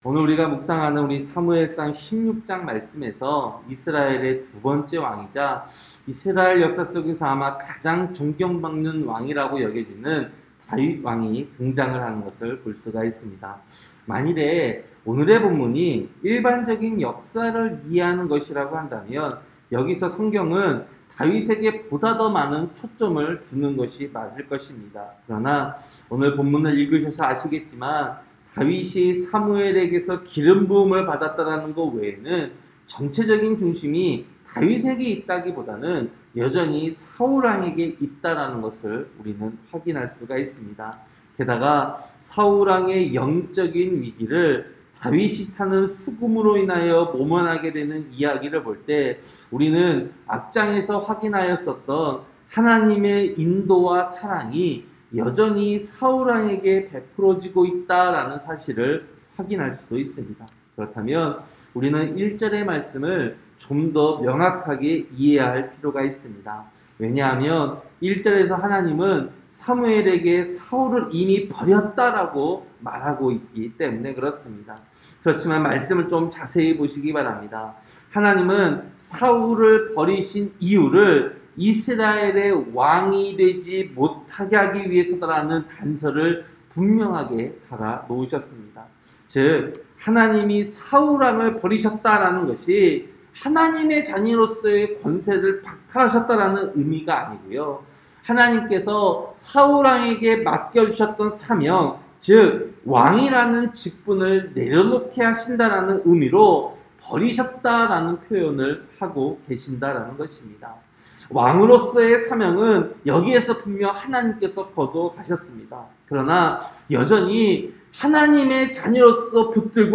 새벽설교-사무엘상 16장